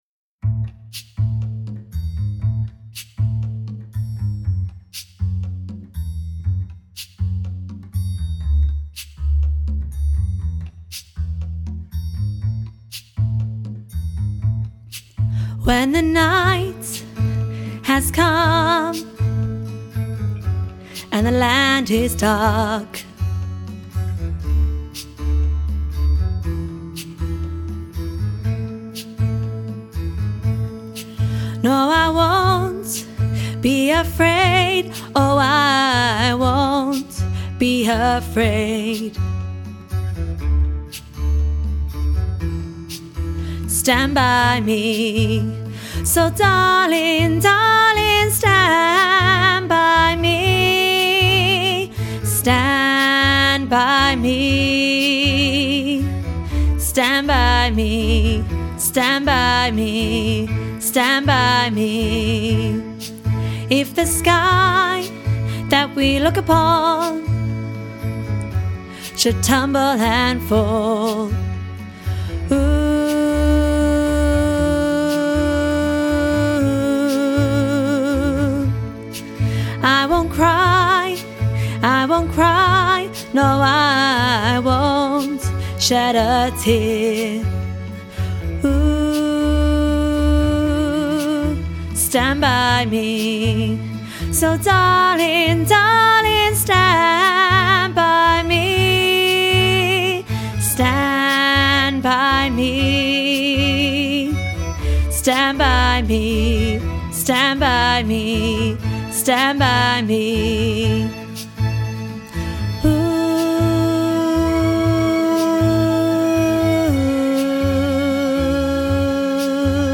stand-by-me-alto.mp3